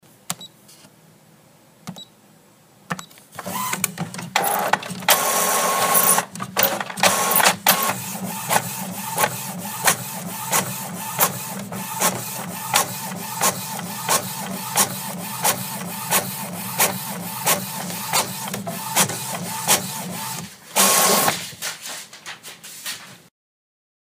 Звуки принтера
На этой странице собраны звуки работающего принтера: от монотонного жужжания лазерных моделей до характерного треска матричных устройств.